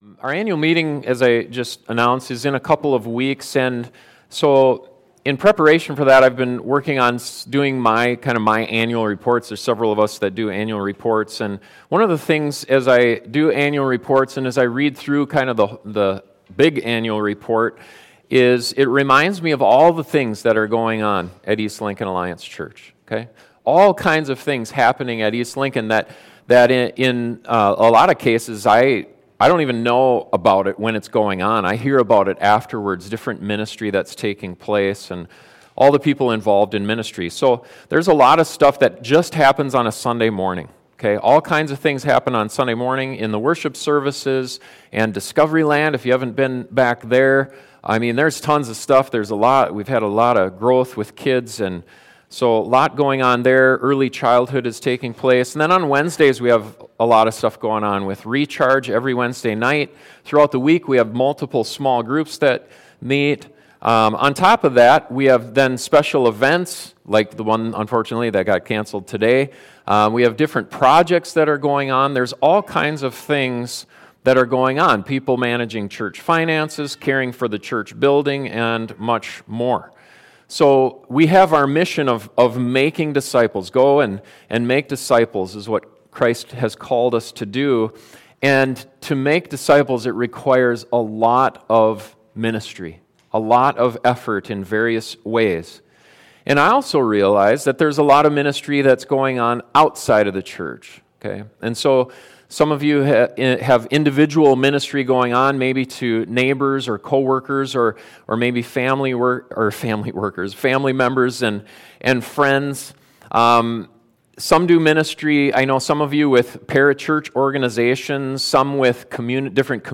Christianity and ministry go hand in hand, but sometimes ministry can be hard and uncomfortable, and we can want to pull back and not be involved. This sermon looks at a time when 72 disciples went out to do difficult ministry and gives us reasons to keep pressing in.